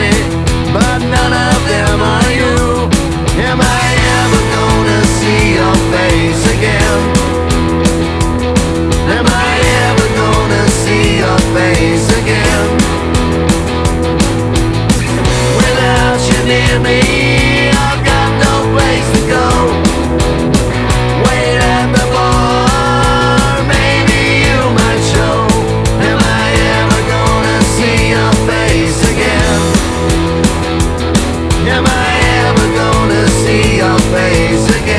# Рок